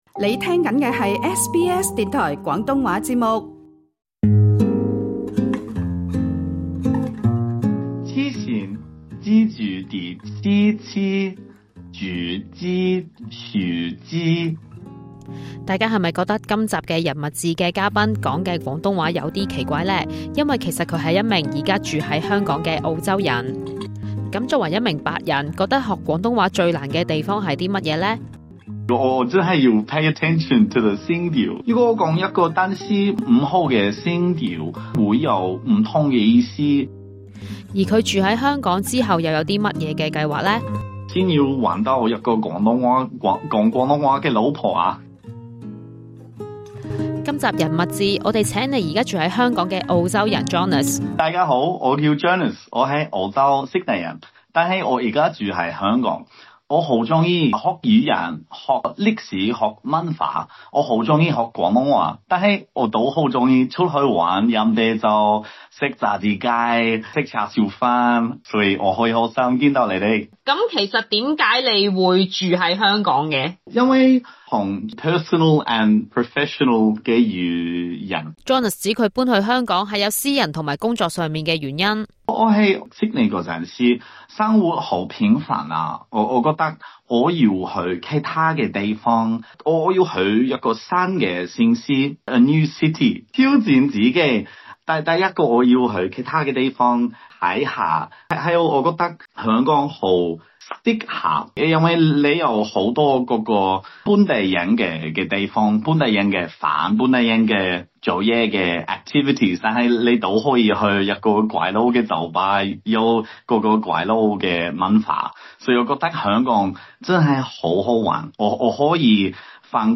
他在訪問中，亦有分享他學習廣東話的趣事跟香港的生活，詳情請收聽這一節《人物誌》。